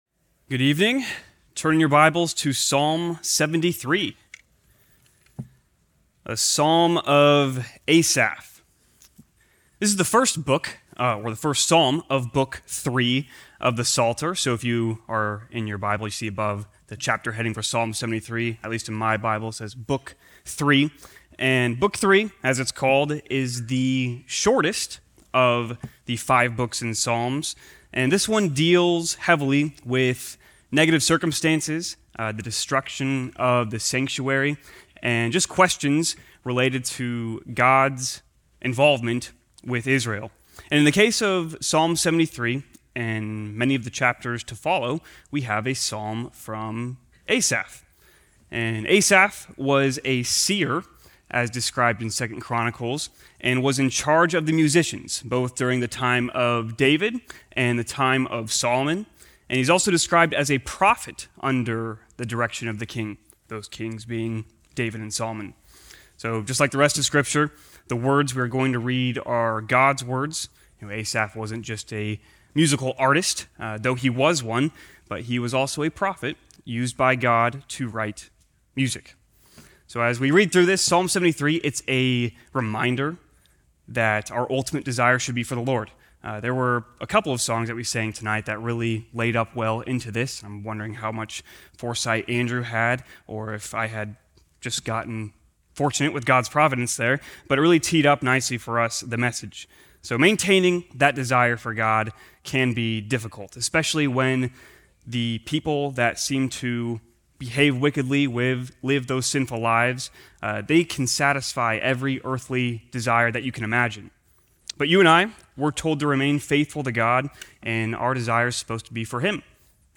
Sermons Besides You